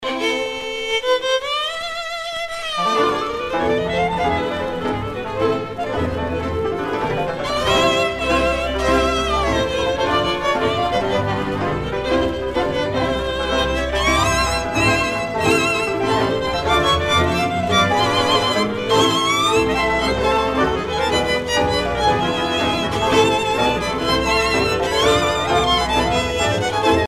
danse : csárdás (Hongrie)
Pièce musicale éditée